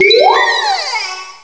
pokeemerald / sound / direct_sound_samples / cries / lampent.aif